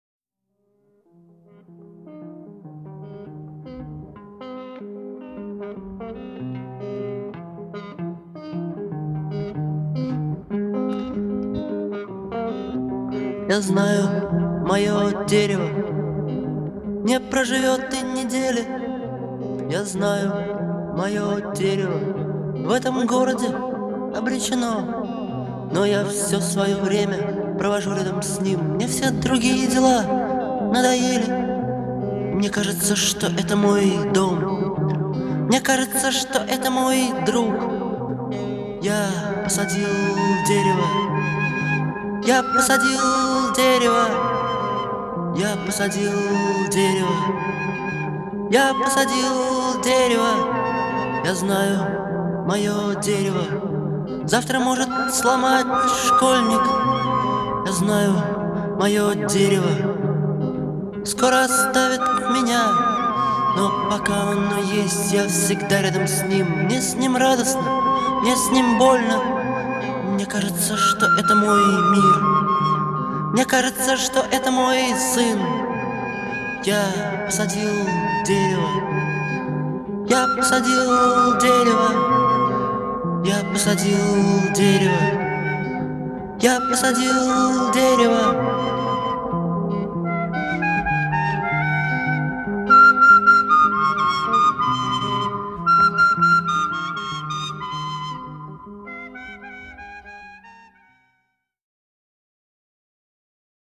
характерные гитарные рифы
мощный вокал